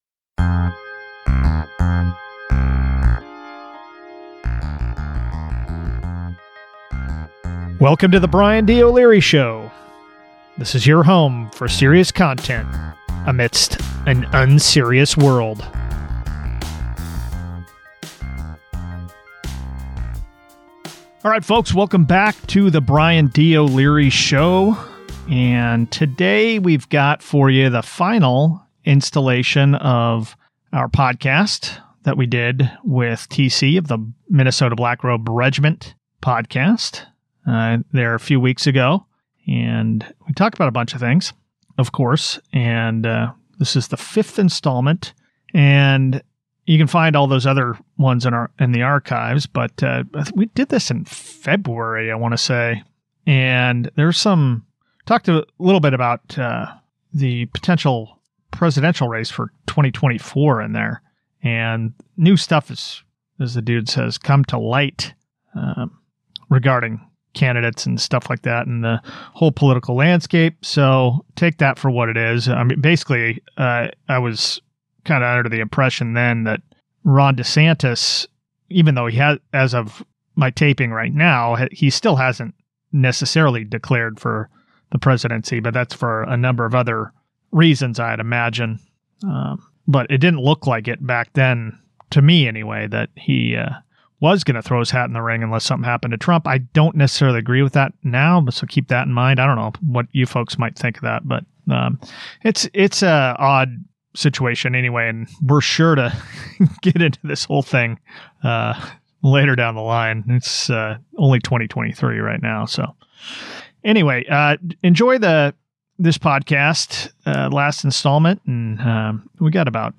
We’ll drop the rest of the interview in pieces over the next few weeks.